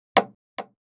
Index of /traerlab/AnalogousNonSpeech/assets/stimuli_demos/repeated_impact/small_plastic_compact_delicup
drop02_down.wav